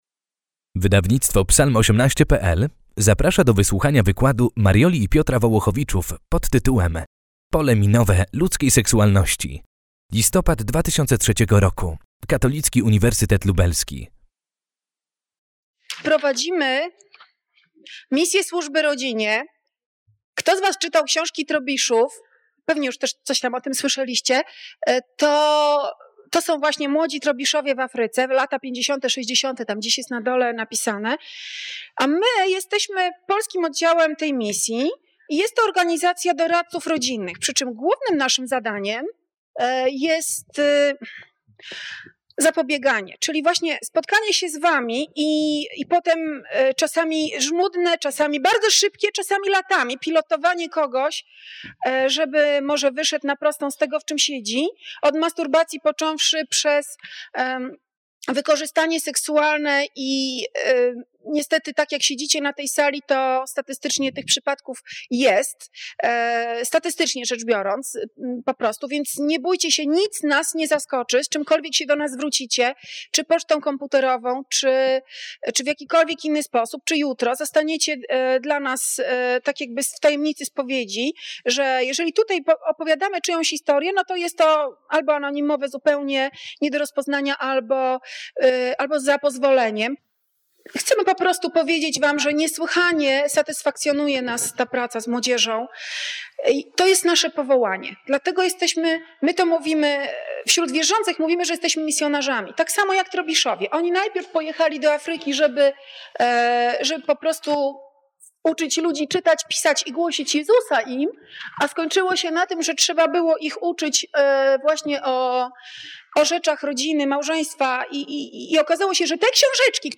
Odpowiedzi na te i inne pytania w wyjątkowym wykładzie dla studentów, który choć wygłoszony w roku 2003, wcale nie stracił na aktualności.